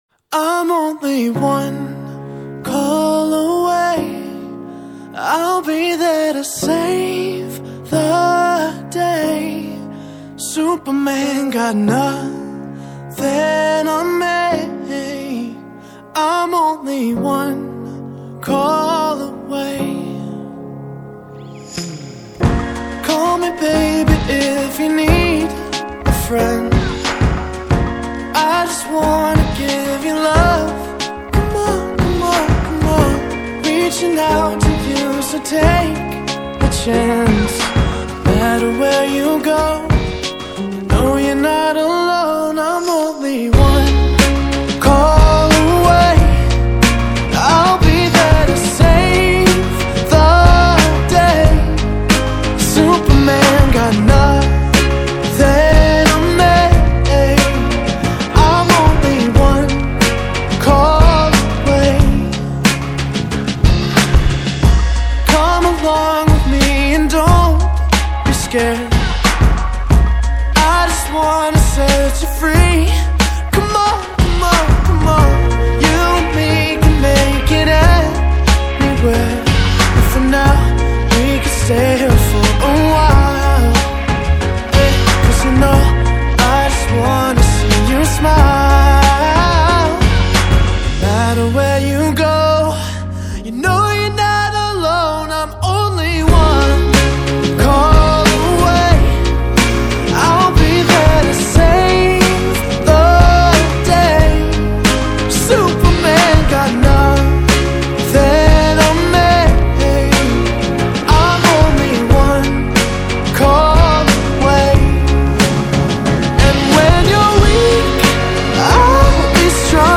Pop / R&B